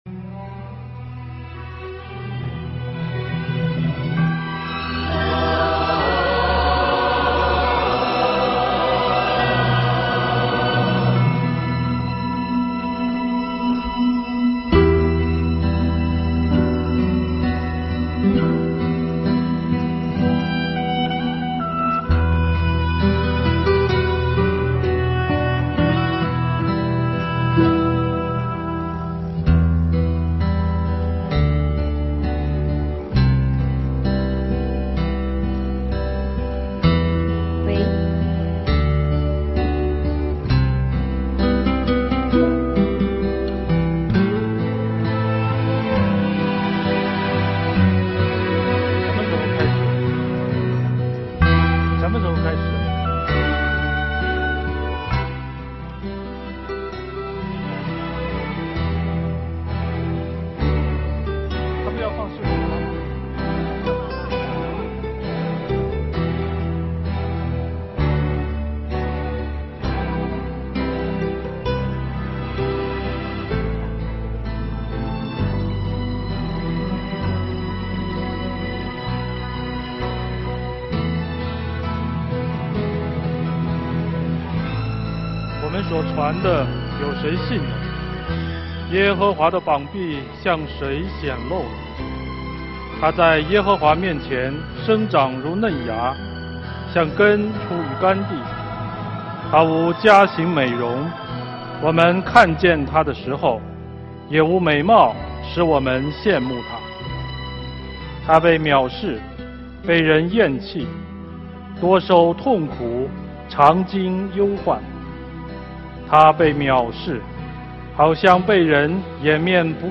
22-04-15受难周受难日|十架七言分享 | 北京基督教会海淀堂